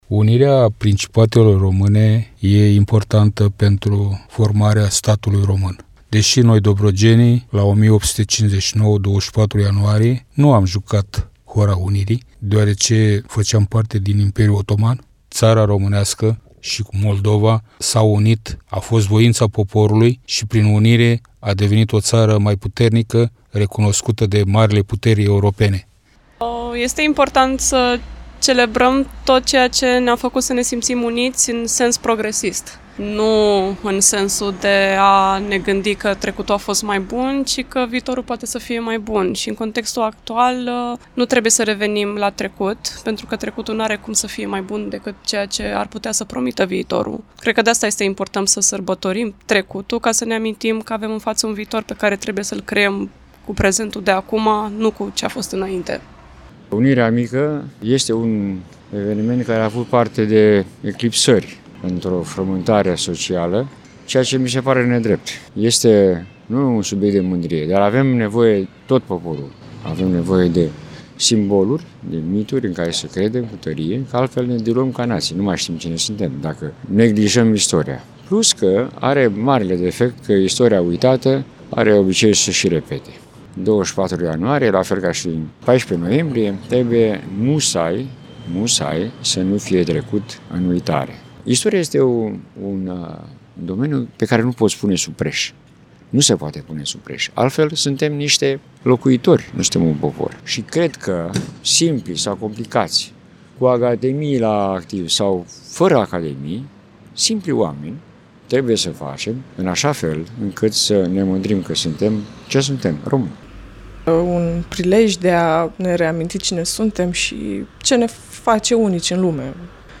Semnificațiile momentului istoric de la 24 ianuarie 1859 și mesajele tulcenilor la celebrarea a 166 de ani de la Unirea Moldovei cu Țara Românească.